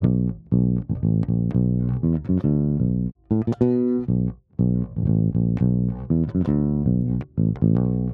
12 Bass PT1.wav